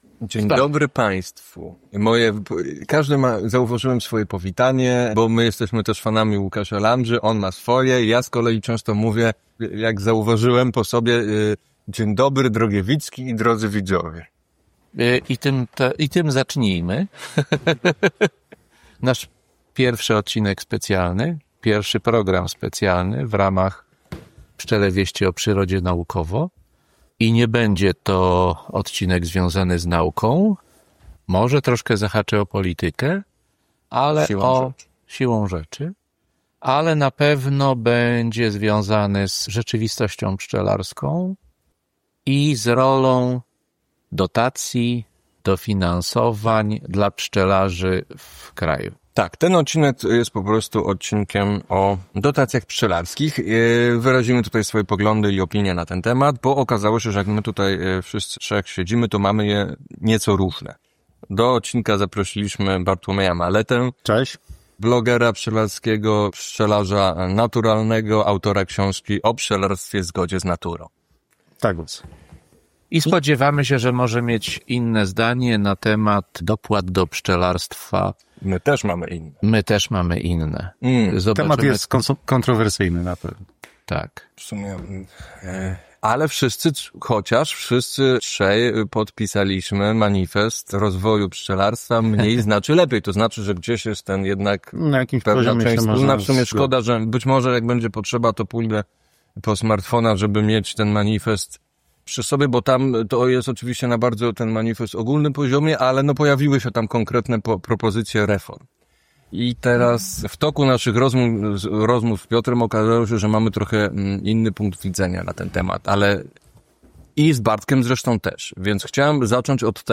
49 Pszczele Wieści - Dyskusja o wizji reform dofinansowania pszczelarstwa.mp3